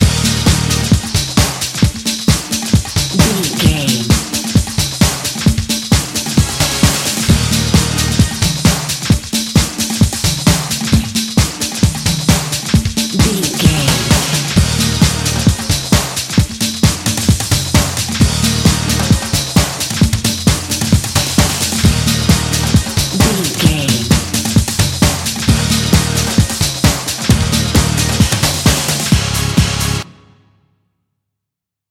Uplifting
Ionian/Major
Fast
drum machine
synthesiser
bass guitar